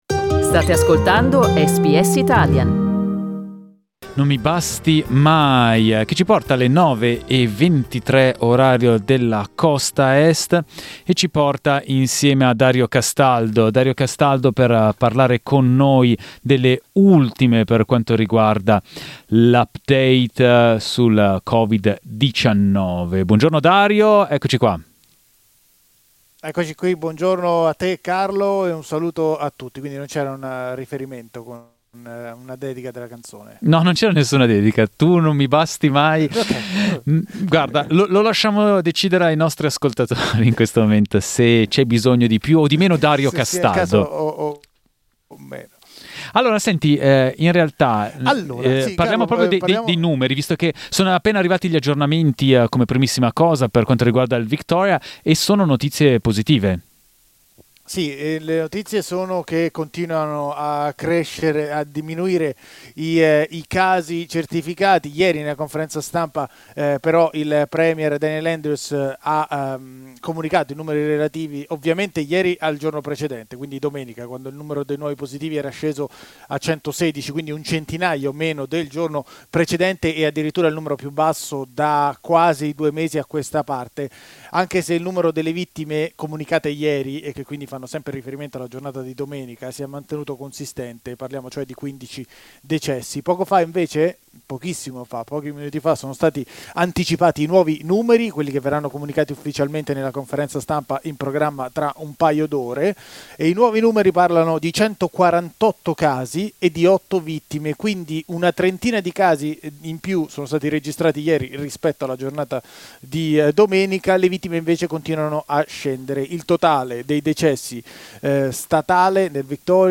Il nostro aggiornamento in diretta sulla situazione del COVID-19, che ha fatto registrare in Victoria 148 nuovi casi e otto decessi, portando il numero nazionale di vittime a 520.